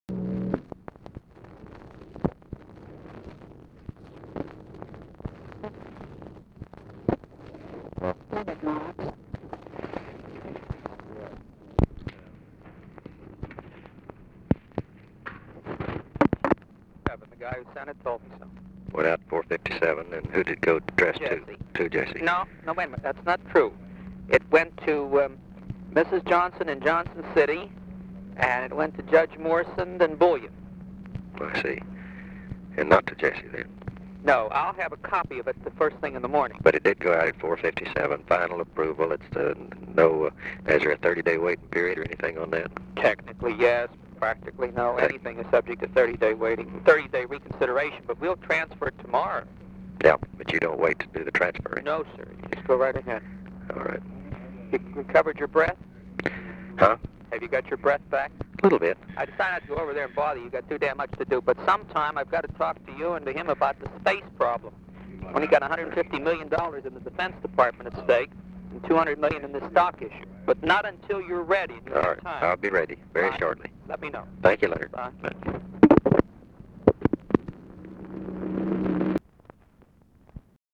Conversation with LEONARD MARKS, December 5, 1963
Secret White House Tapes